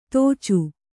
♪ tōcu